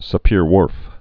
(sə-pîrwôrf, -hwôrf)